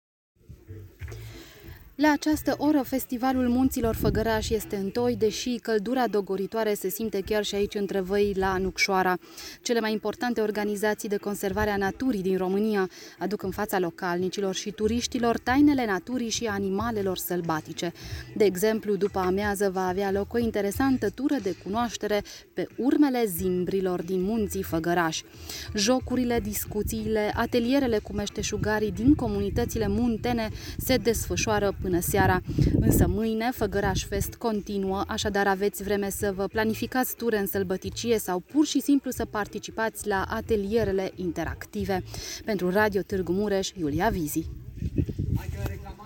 Festivalul comunităților locale din Făgăraș, a doua ediție, se desfășoară anul acesta in Nucșoara.